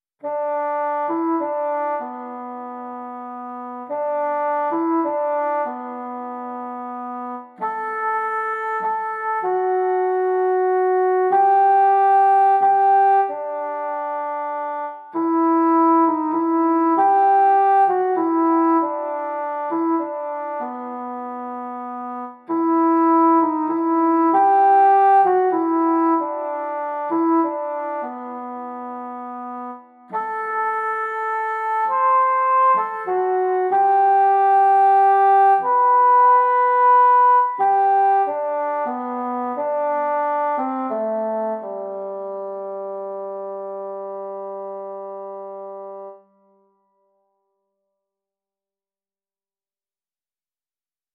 Сольная партия фагота